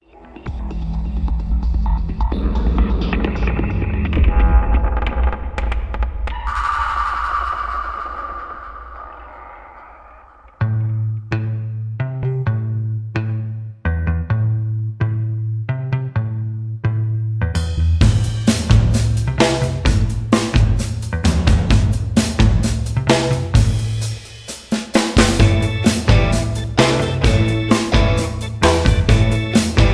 Aus dem Kindertanztheater